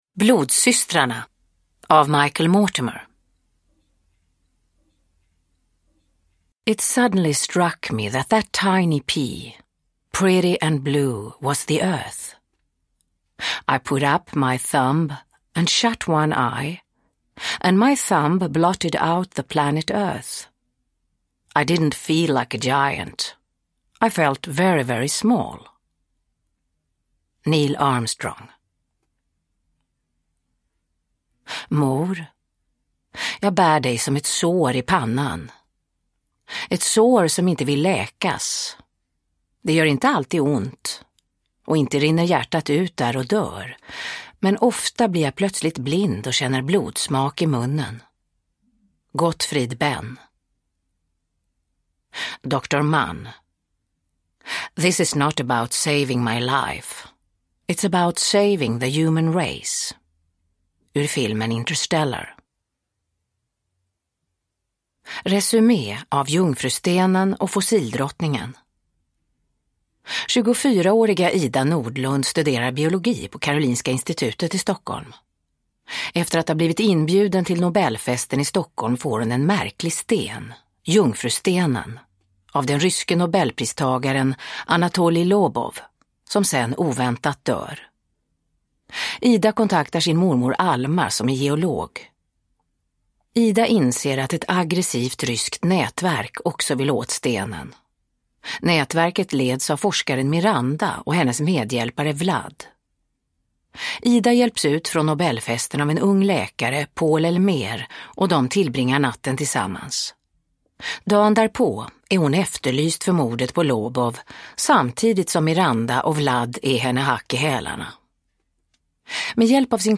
Blodssystrarna – Ljudbok
Uppläsare: Marie Richardson